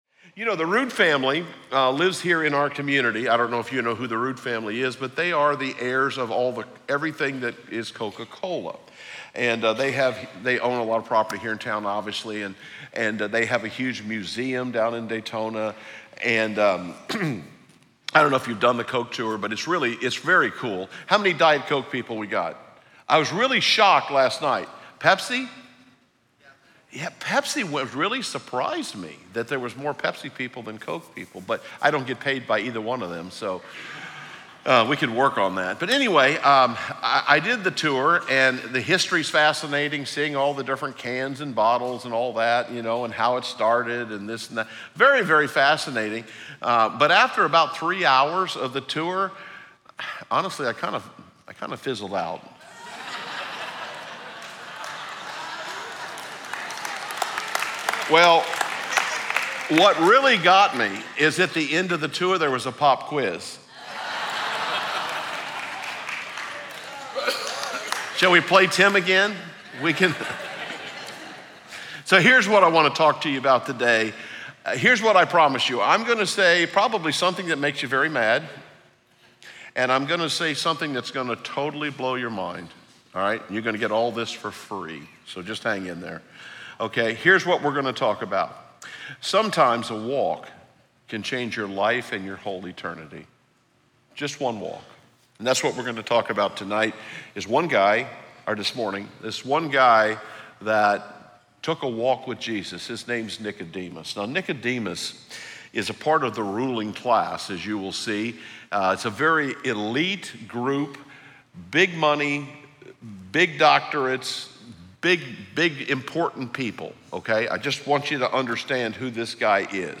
Tomoka Christian Church provides dynamic and inspiring messages that are relevant and Bible-based.